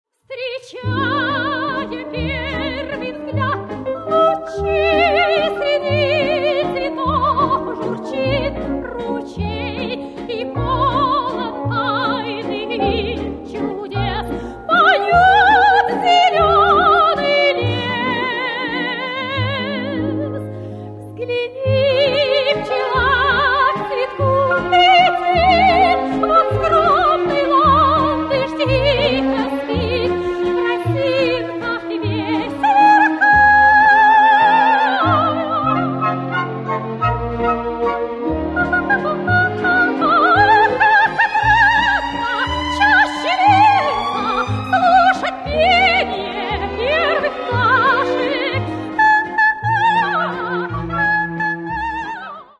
Каталог -> Классическая -> Опера и вокал